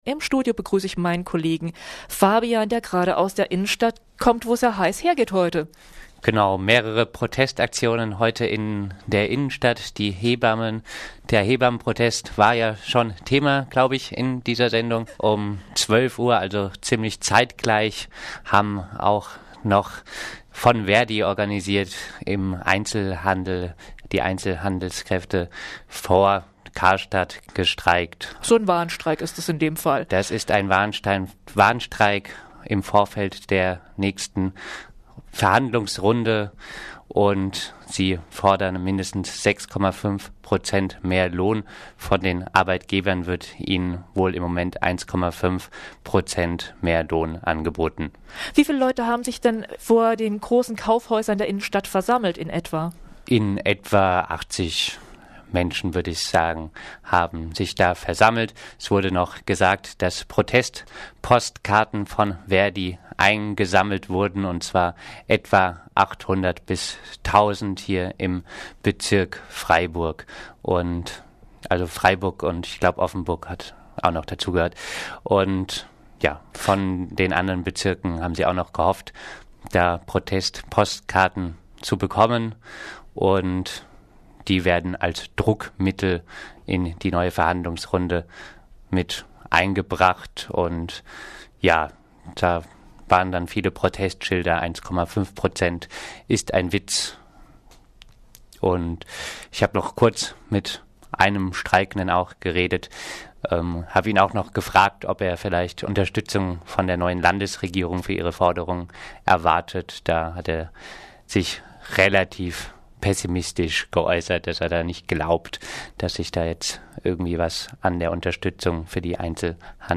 Die Dienstleistungsgewerkschaft Verdi hat für heute, Donnerstag den 5. Mai, zu Streiks im Freiburger Einzelhandel aufgerufen. Ein Bericht